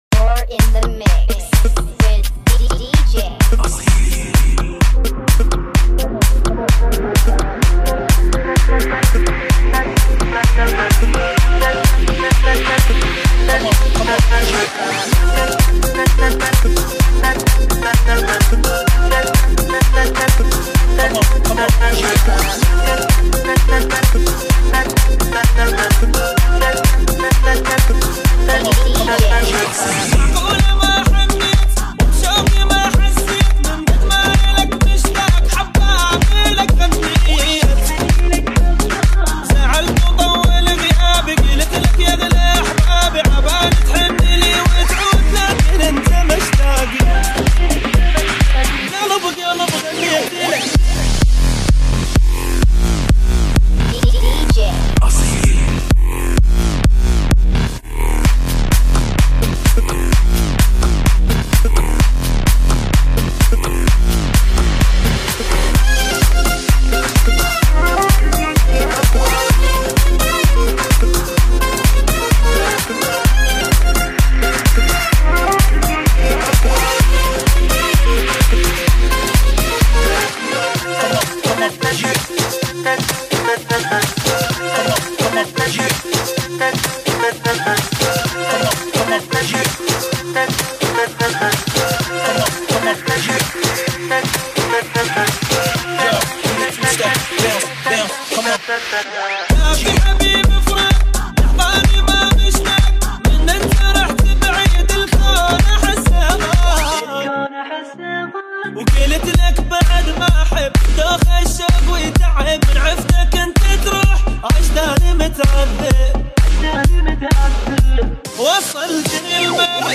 Remix ريمكس